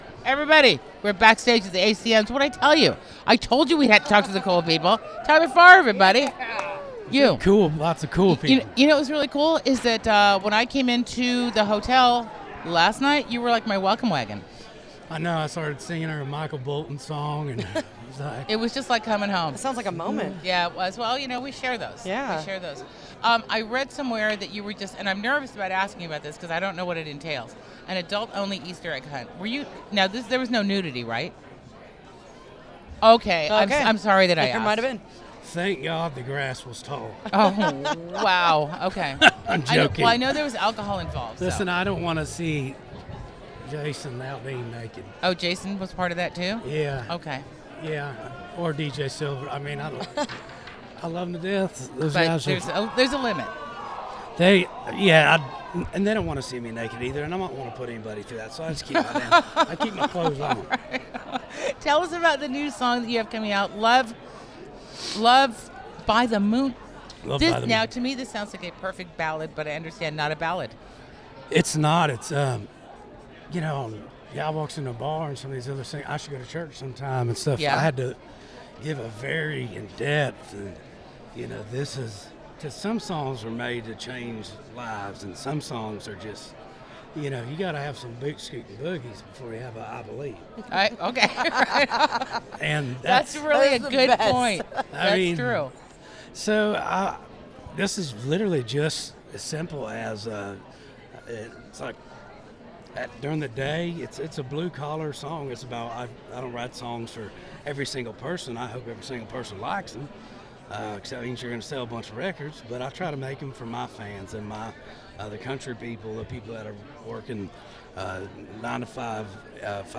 Tyler Farr Interview At 2018 ACMs!